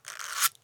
Matches 03.wav